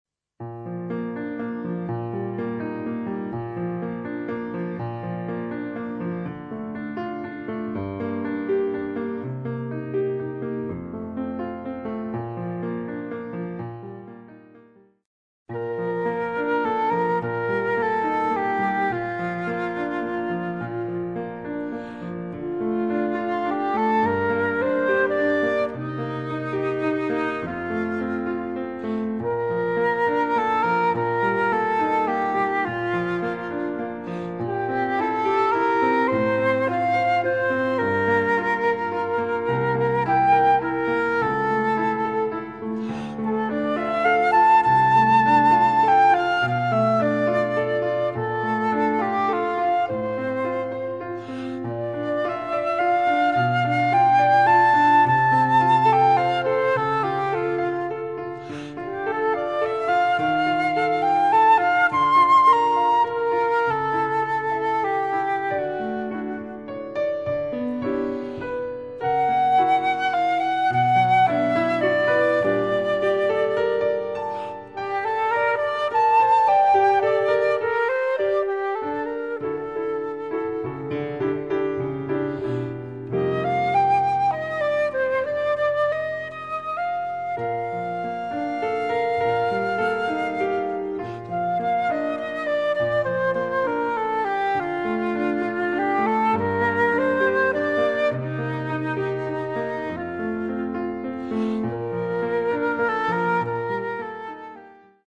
Solo pour flûte, ou clarinette, ou saxophone alto + piano.